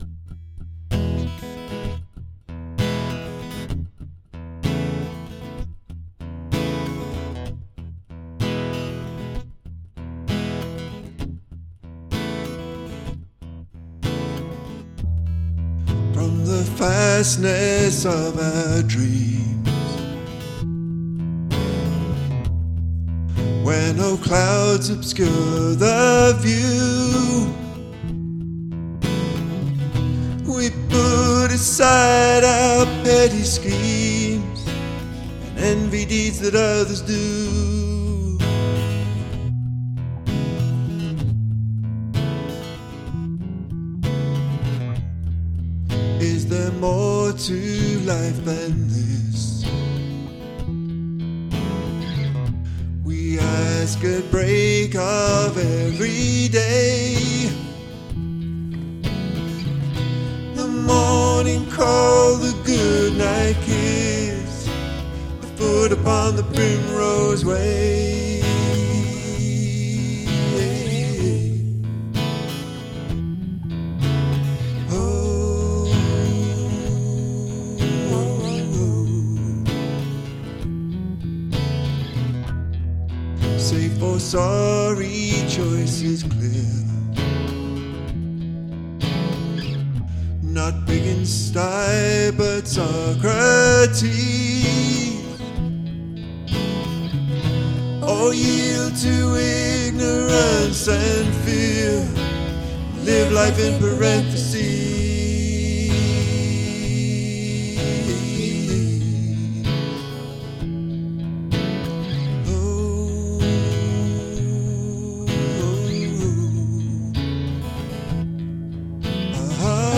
acoustic/electric trio
bass guitar & bouzouki